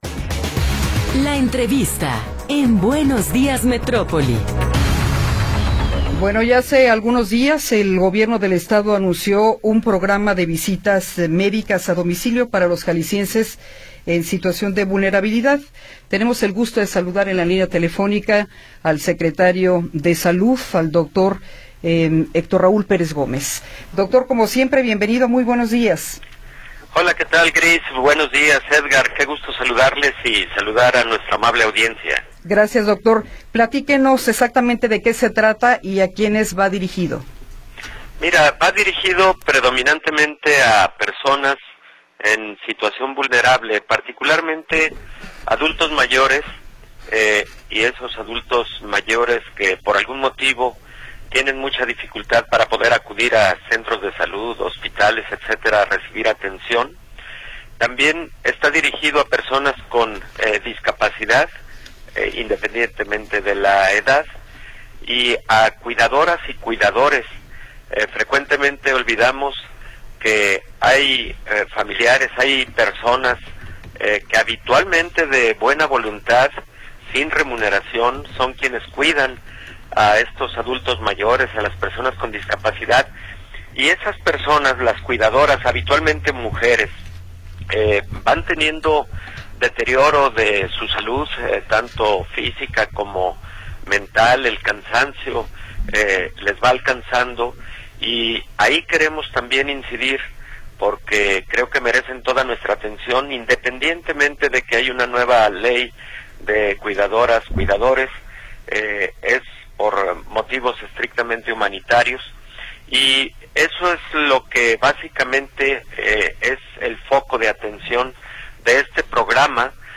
Entrevista con el Dr. Héctor Raúl Pérez Gómez